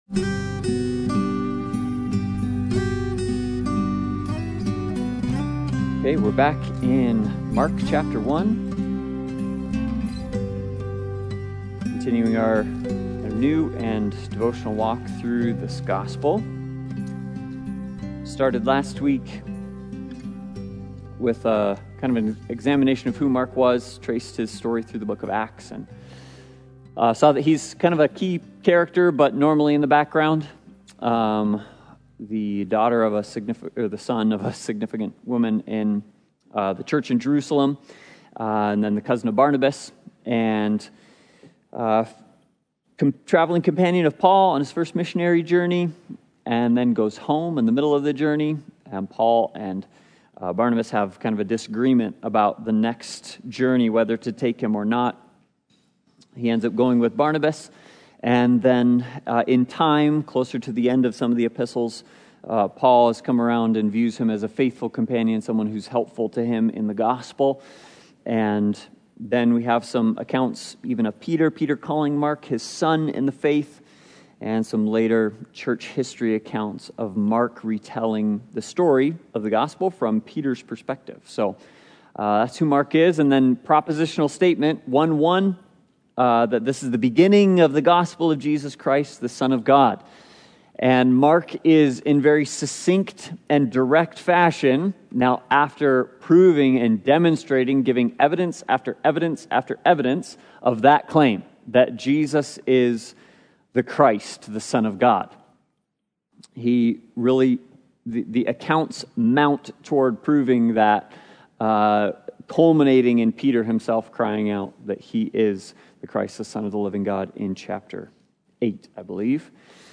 The Gospel According to Mark Service Type: Sunday Bible Study « A New Set of Clothes